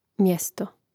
mjȅsto mjesto